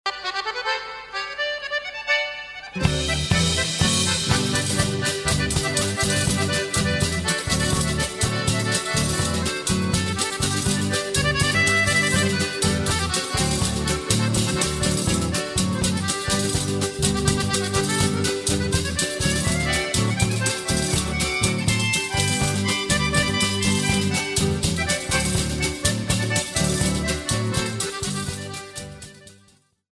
La Cueca
Introducion musical a un pié de cueca - PLAY ( Esta parte de la musica de un pié de cueca es libre y no se contabiliza como parte de su extructura musical.)
muestracuecas  .mp3